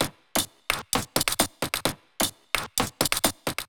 TT_loop_brazilian_wax_130.wav